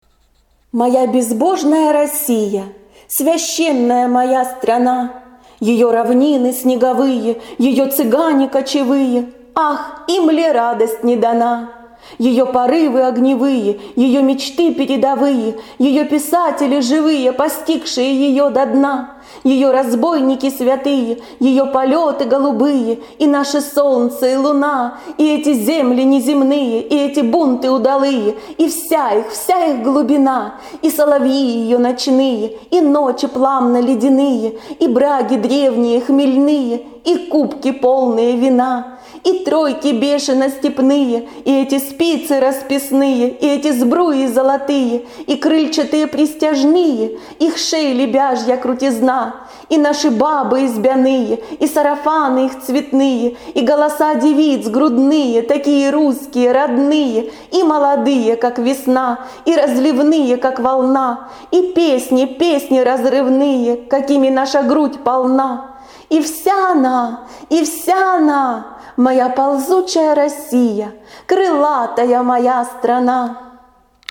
Песни на стихотворение: